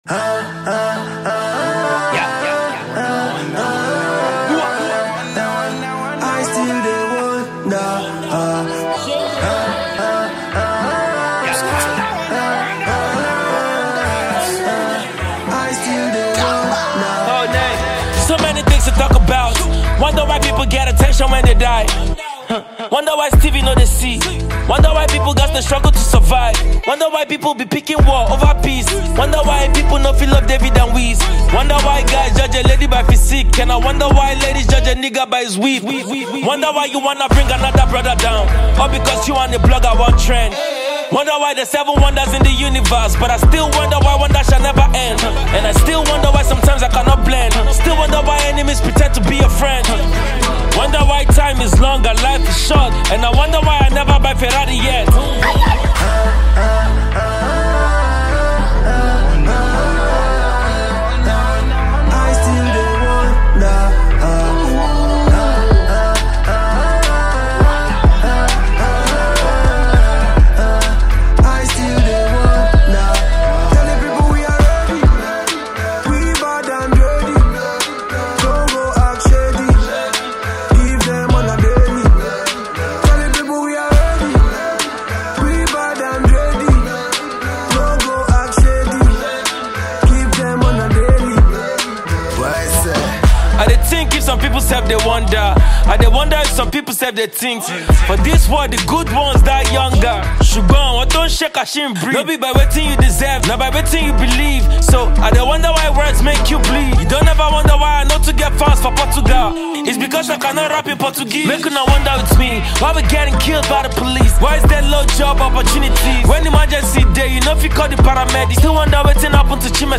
banging tune